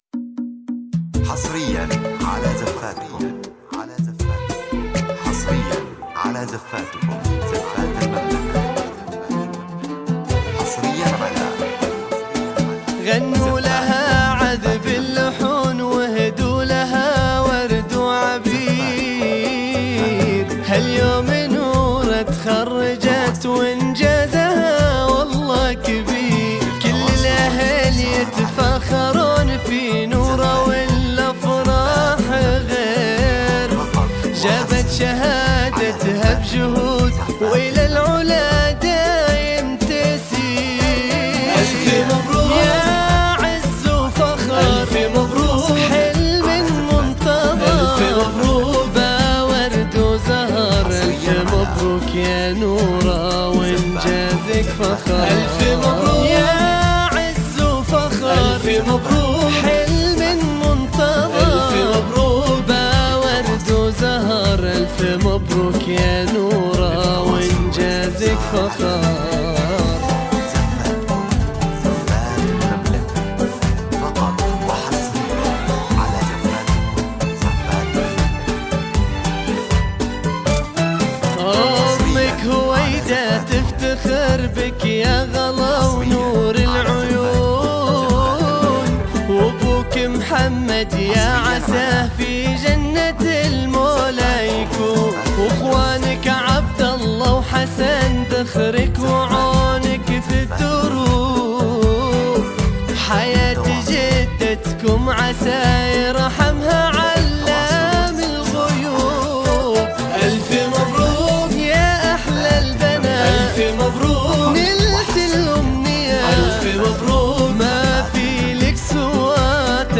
زفات تخرج